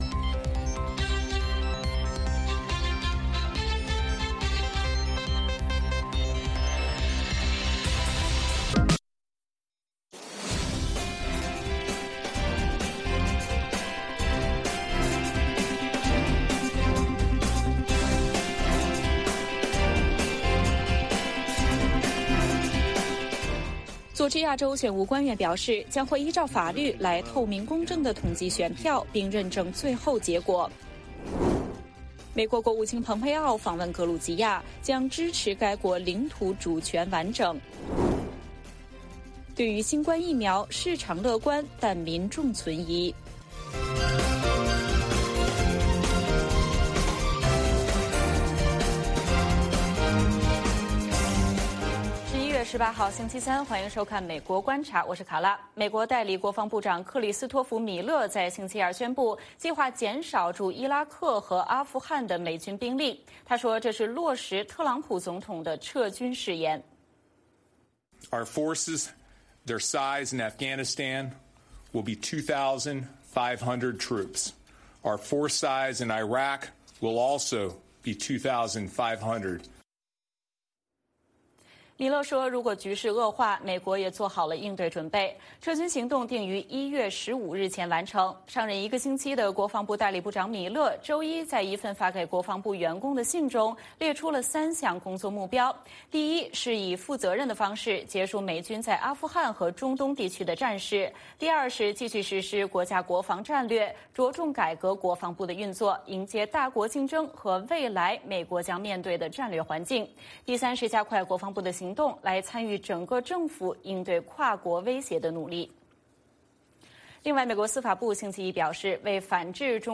美国观察(2020年11月18日)美国国务卿蓬佩奥的首席中国政策和规划顾问余茂春接受美国之音专访。